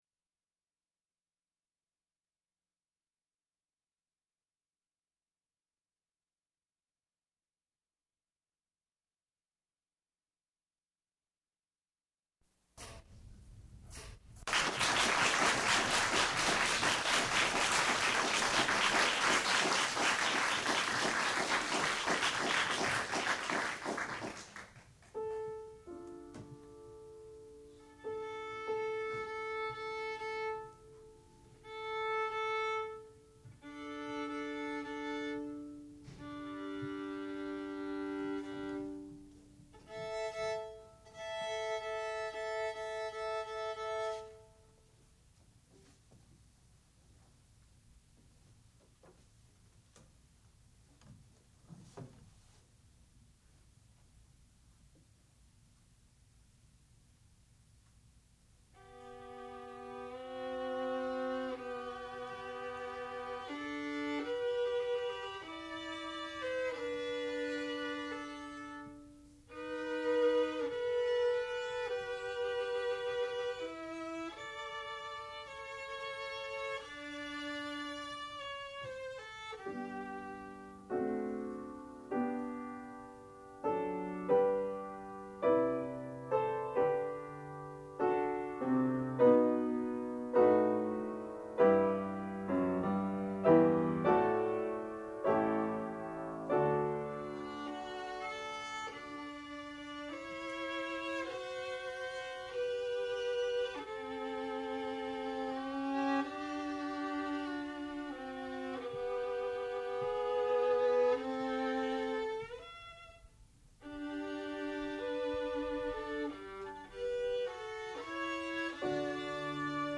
sound recording-musical
classical music
violin
piano